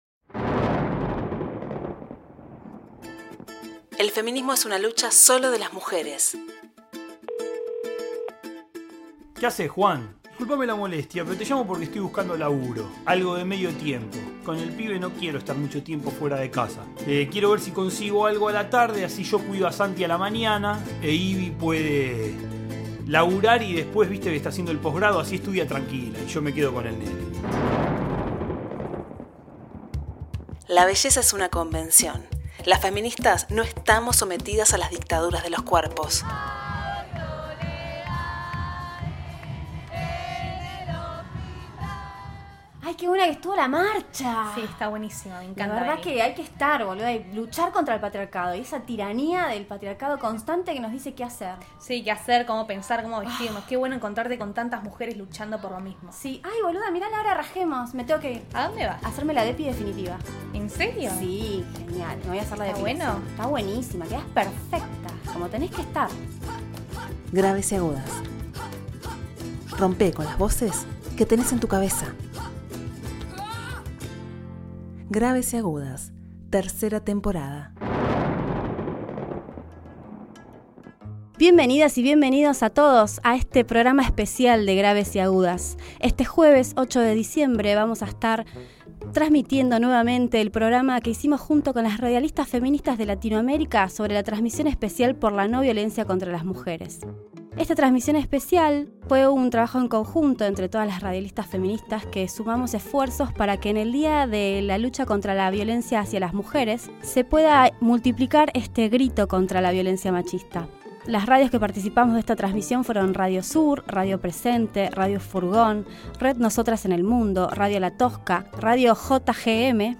Radialistas feminista de Argentina, Chile, Uruguay organizamos una transmisión colectiva feminista por el 25 de noviembre - Día Internacional contra la Violencia hacia las Mujeres. Compartimos un repaso de la situación de los derechos en las mujeres en la región.